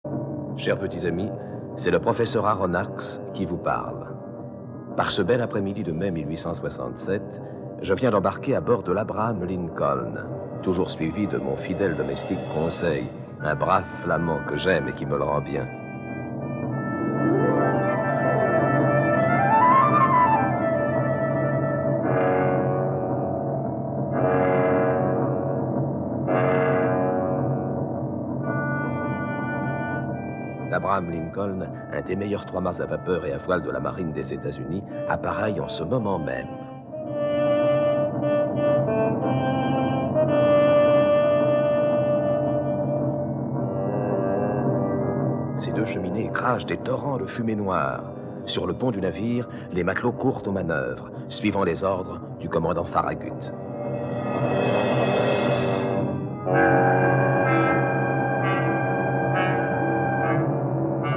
Diffusion distribution ebook et livre audio - Catalogue livres numériques
Adaptation audio faite en 1956, avec Jean Gabin dans le rôle du capitaine Némo